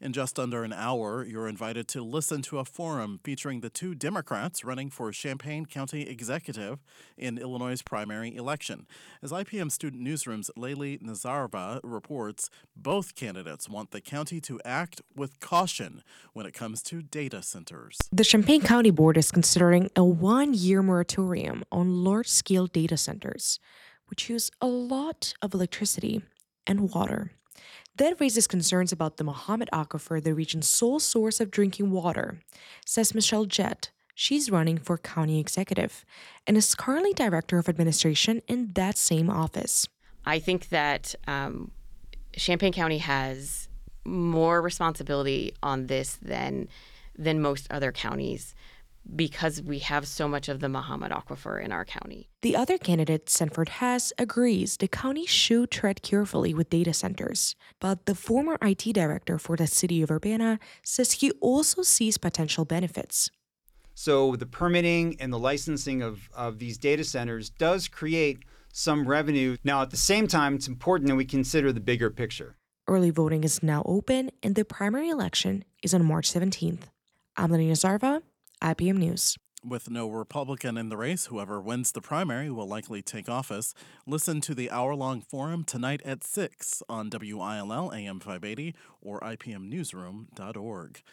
At IPM News forum, Champaign County Executive candidates discuss data centers, ICE and local finances
URBANA – The two Democrats running for Champaign County Executive shared their views on data centers, federal immigration enforcement and whether the position of county executive should be appointed or elected, at a candidate forum hosted by IPM News.
ChampaignCountyexecforum.mp3